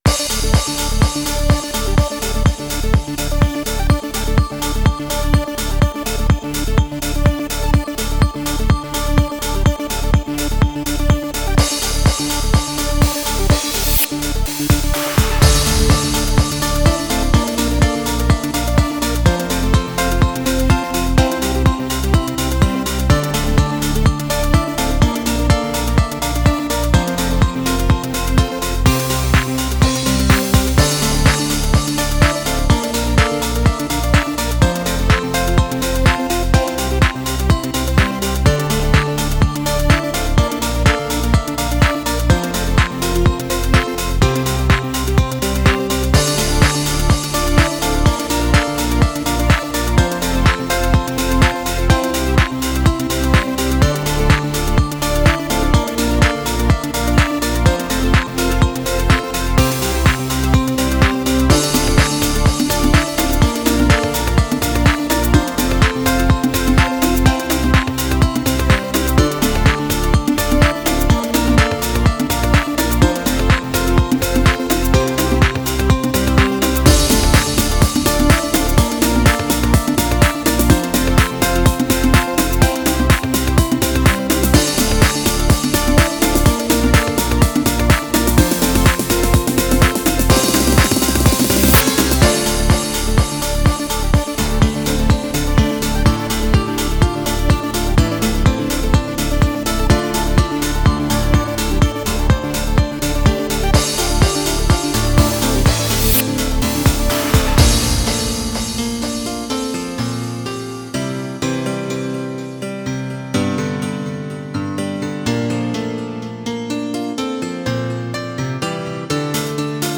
HOUSE（BPM１２５）
イメージ：地平線　ジャンル：Dream House、Piano House